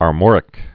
(är-môrĭk, -mŏr-) also Ar·mor·i·can (-ĭ-kən)